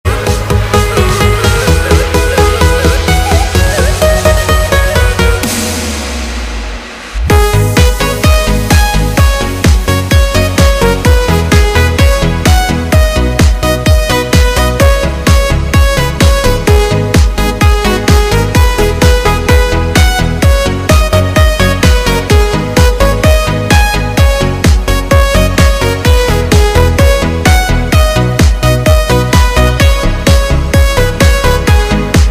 纯音乐DJ